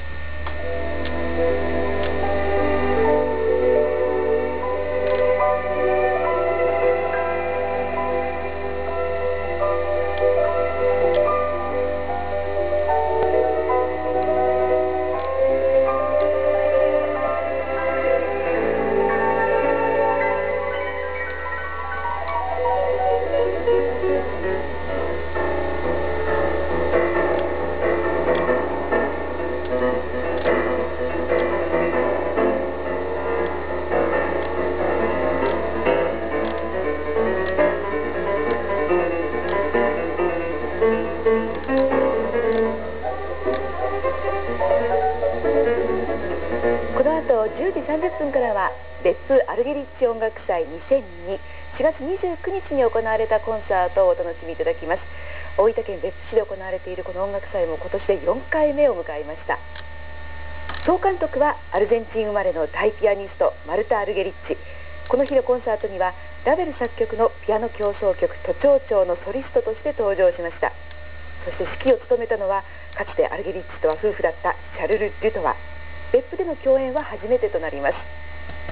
♪Ravel from Music Festival: Argerich's Meeting Point in Beppu '02 (芸術劇場)♪